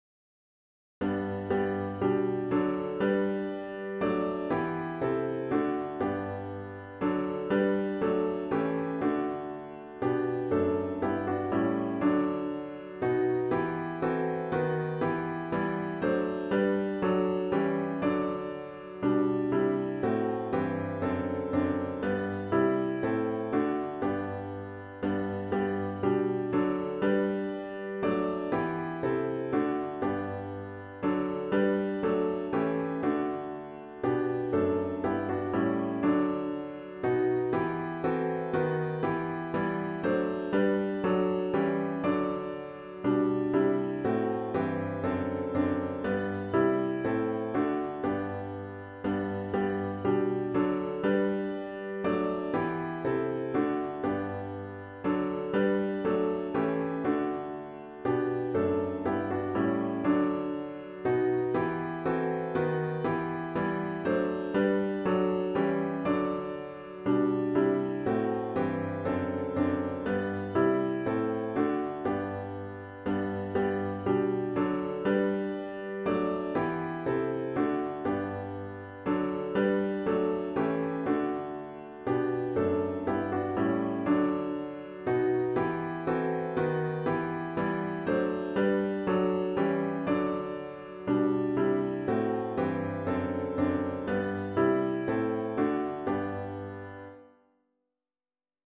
OPENING HYMN  “You Servants of God, Your Master Proclaim”  GtG 299  (Verses 1, 2, & 4)